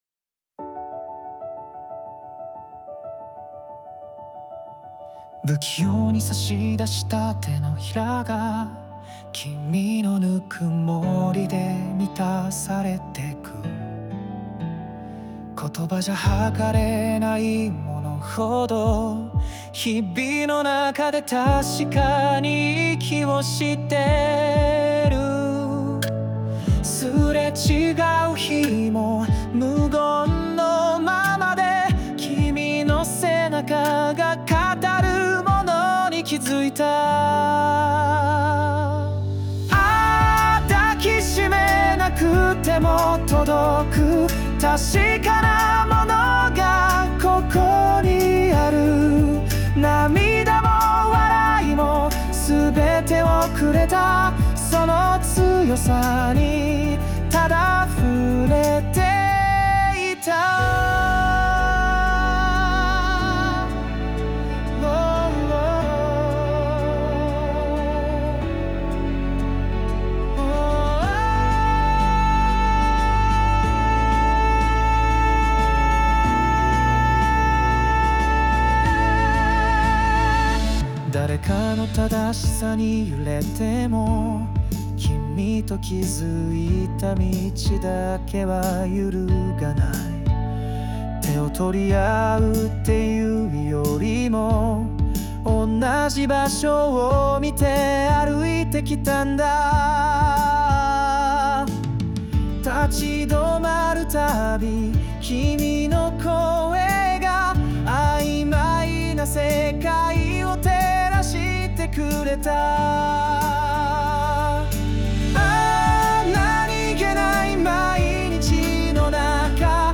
著作権フリーオリジナルBGMです。
男性ボーカル（邦楽・日本語）曲です。
優しさと深さを持つこの曲は、誰かを大切に思うすべての人の心に、きっと届くはず。